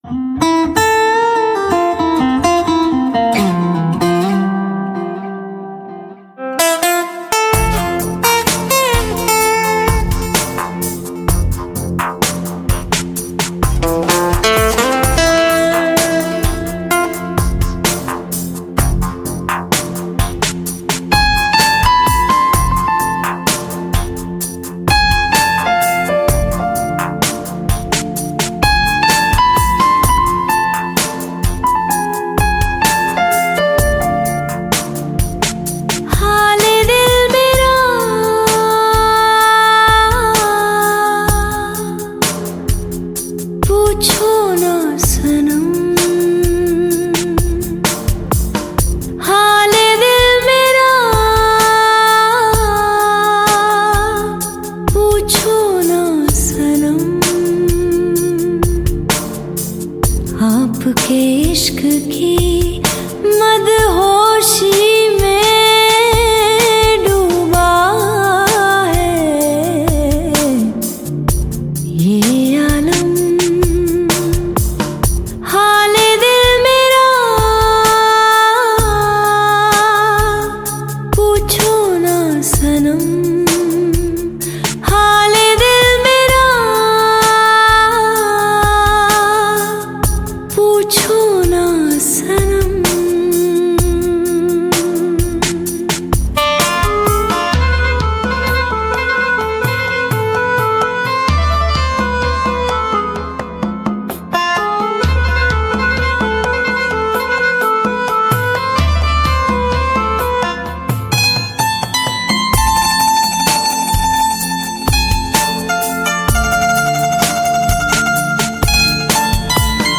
Bollywood track